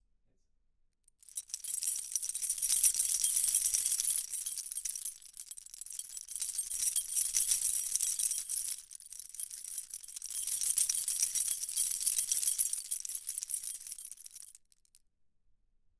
Kurze, Impulsive Schallereignisse werden sehr detailliert eingefangen und »verschwimmen« nicht.
Klangbeispiele des Mojave MA-50
mojave_ma-50_testbericht_schluessel.mp3